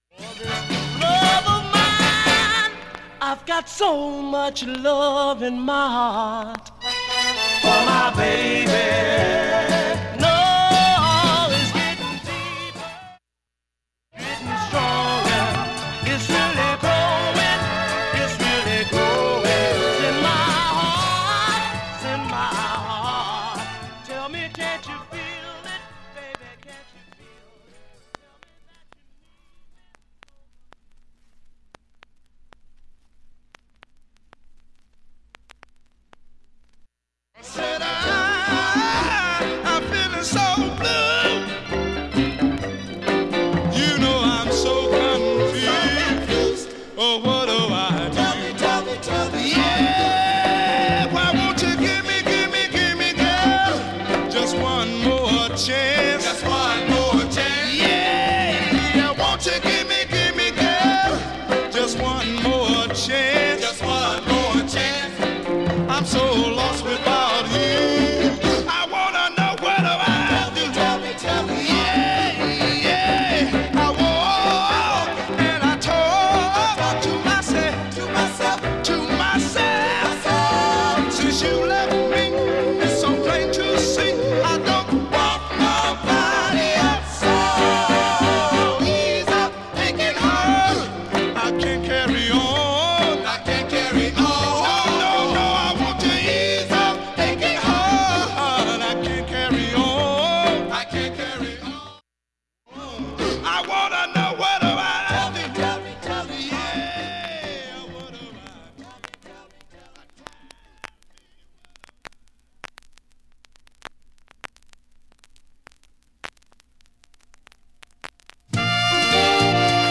音質良好全曲試聴済み。
2,(37s〜)B-2序盤60秒間かすかプツ出ます。
3,(1m43s〜)B-3始め90秒間かすかプツ出ますが